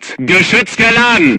hit5.ogg